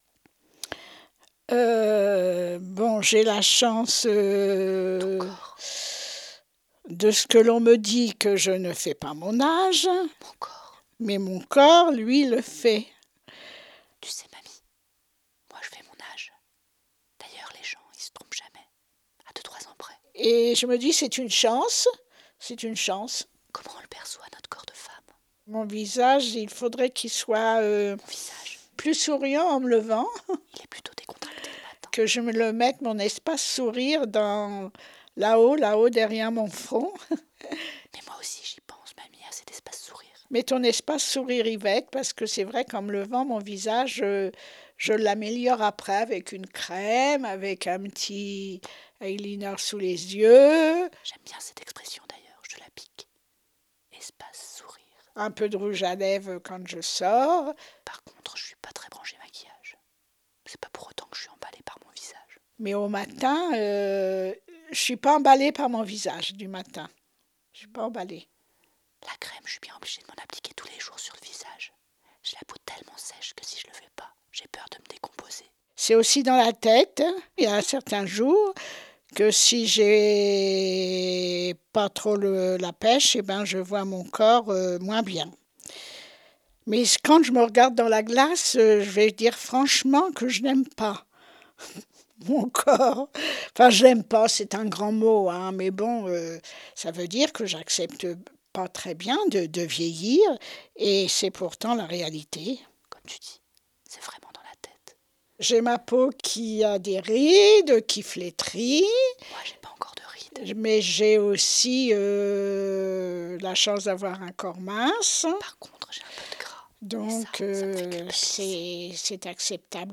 Intime Peau d’Croco Deux femmes d’âges différents, deux perceptions des corps, une grand-mère et sa petite fille se répondent à distance…